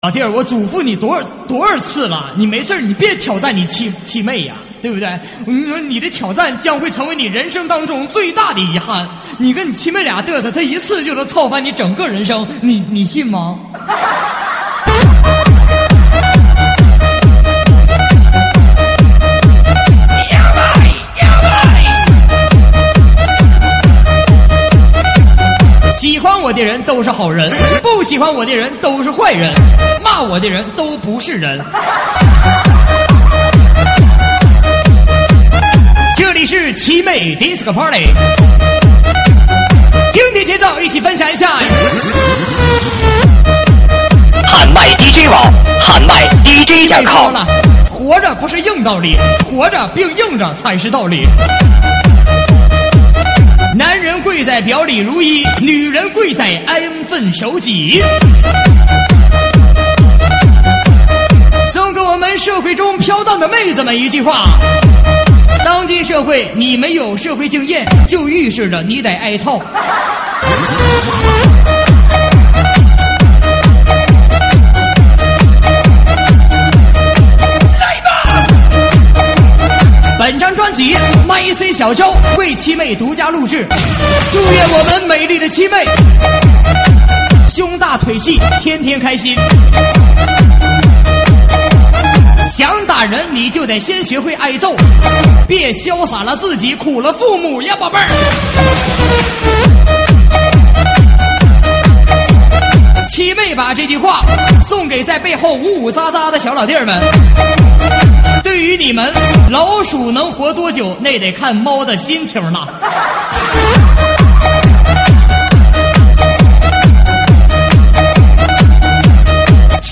慢摇舞曲
舞曲类别：慢摇舞曲